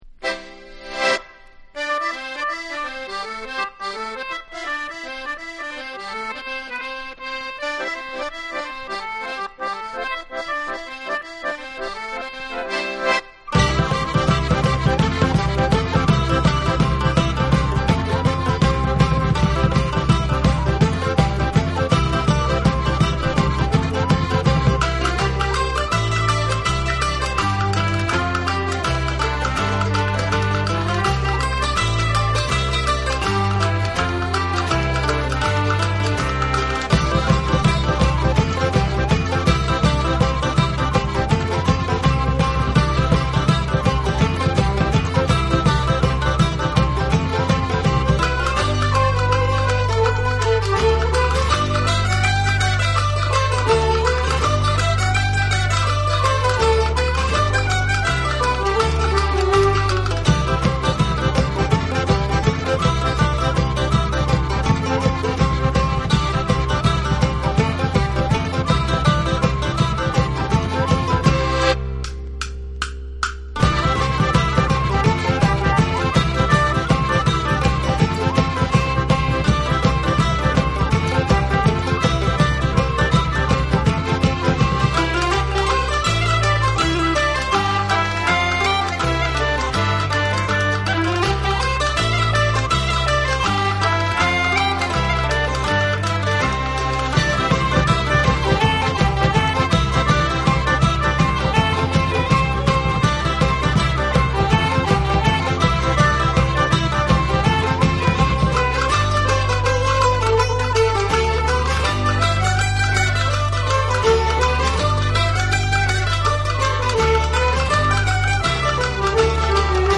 アイリッシュ・フォーク/トラッドにパンクのアティテュードを注入したバンド
NEW WAVE & ROCK